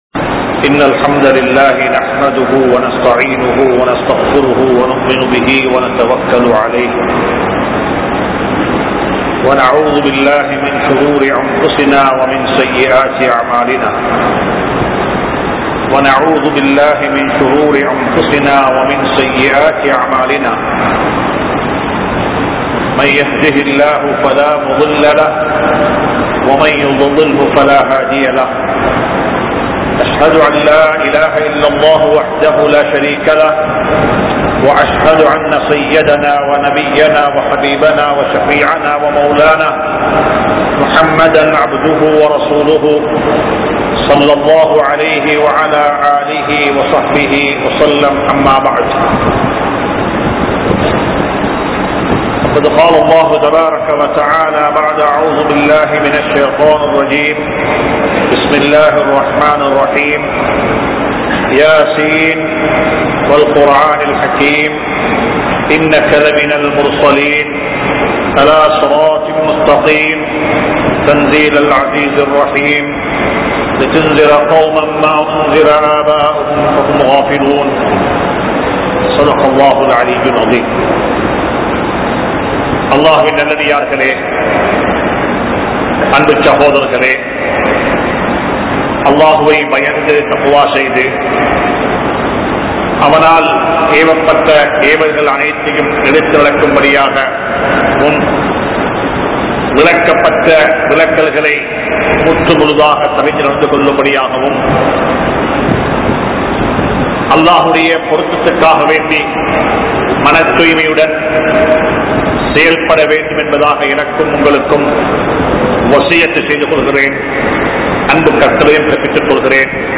Sura Yasin | Audio Bayans | All Ceylon Muslim Youth Community | Addalaichenai
Kollupitty Jumua Masjith